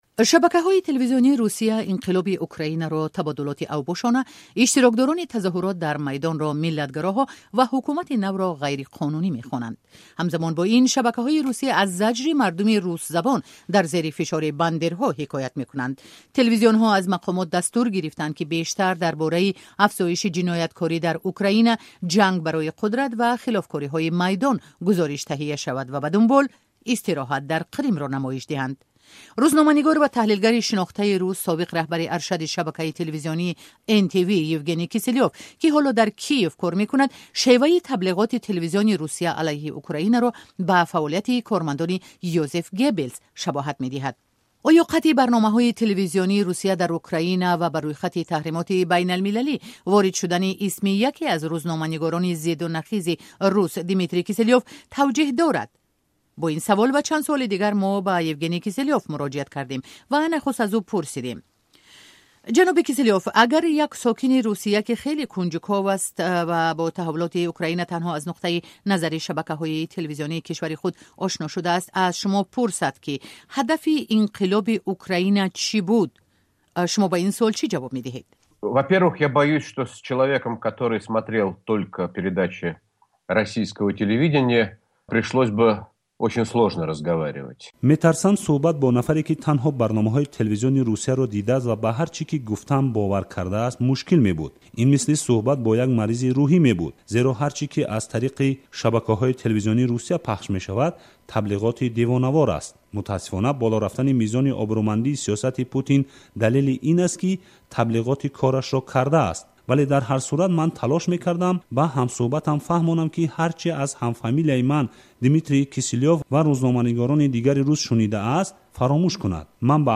Гуфтугӯи Озодӣ бо Евгений Киселёв